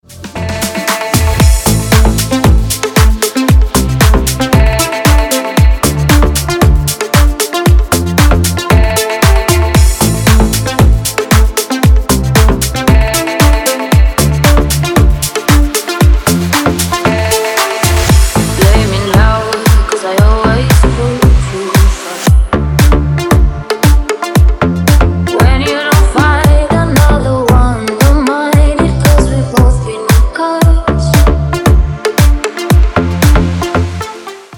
• Качество: Хорошее